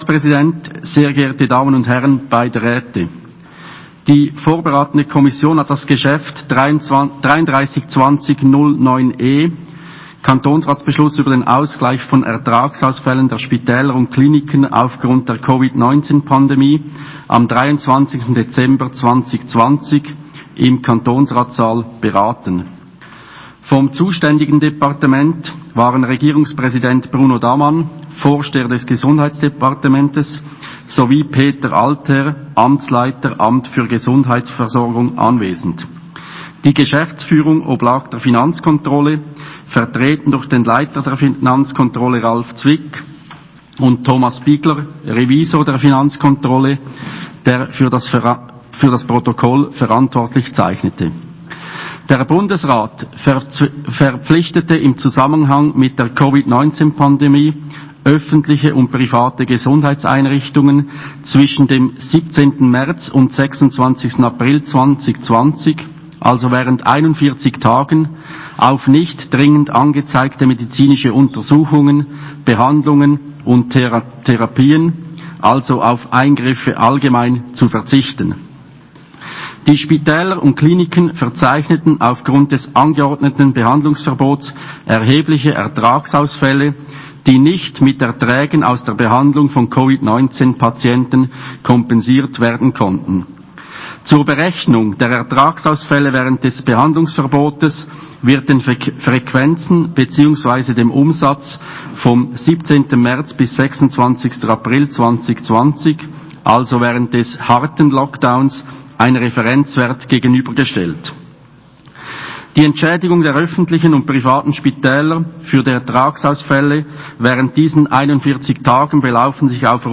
Session des Kantonsrates vom 15. bis 17. Februar 2021